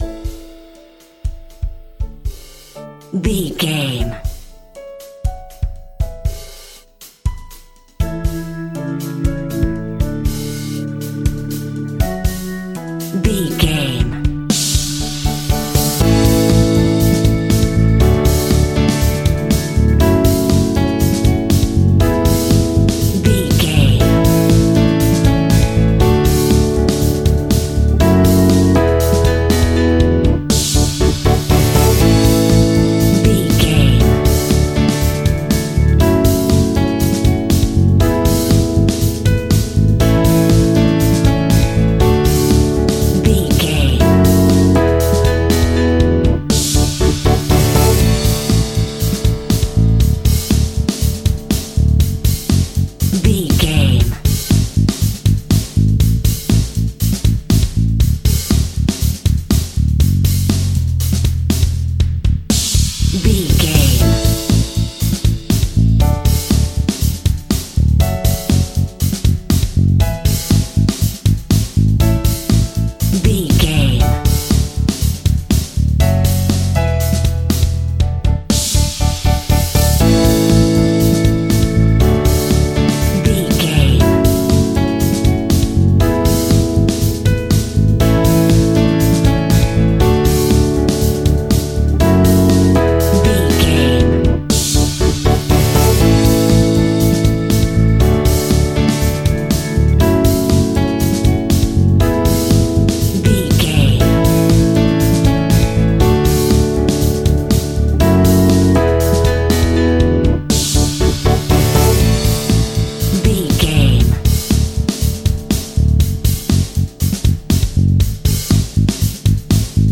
Ionian/Major
indie pop
fun
energetic
uplifting
cheesy
instrumentals
upbeat
groovy
guitars
bass
drums
piano
organ